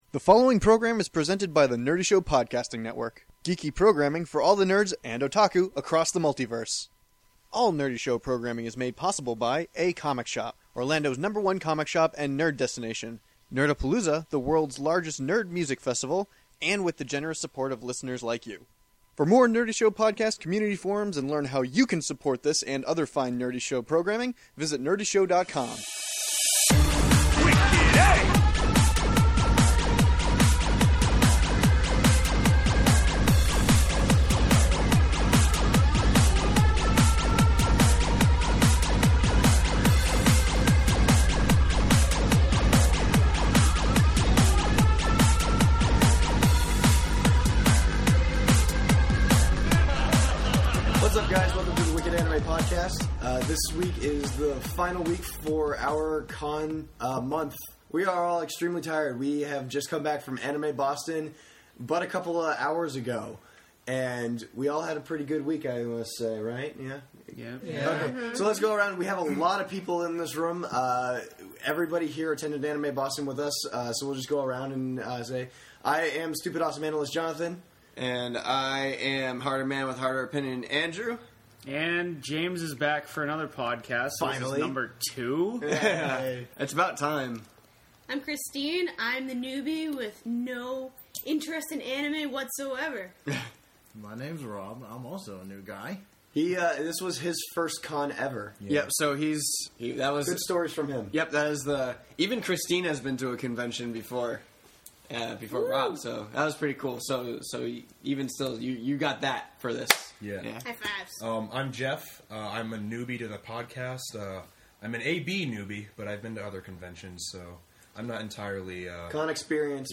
Listen to us as we sit in a circle and talk about how we had such a good time at a place you weren’t even at!